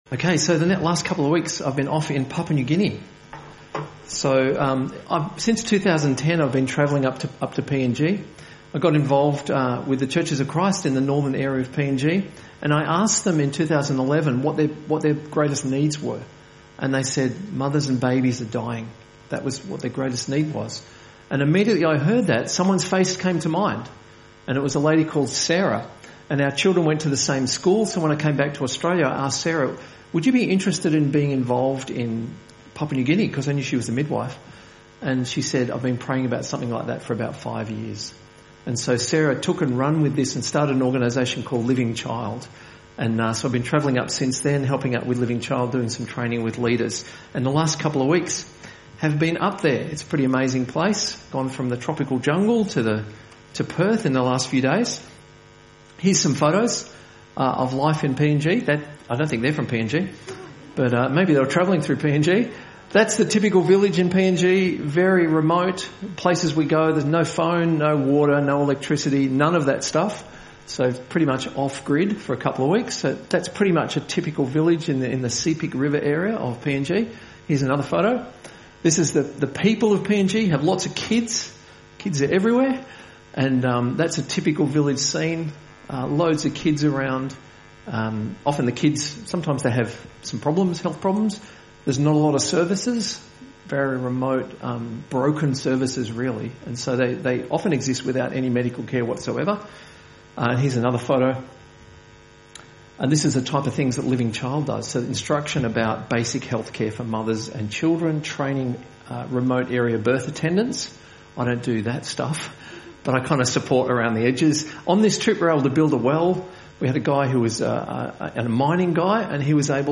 A message from the series "Walking Together."